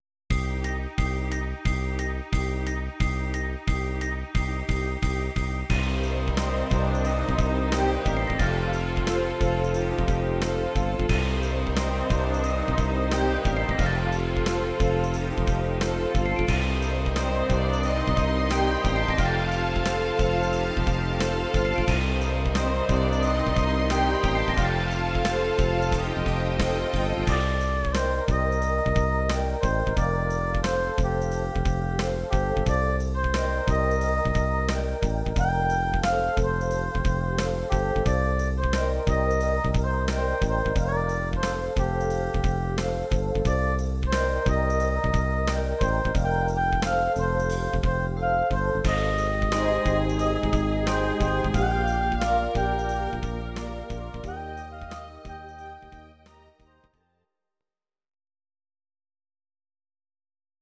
These are MP3 versions of our MIDI file catalogue.
Your-Mix: Rock (2958)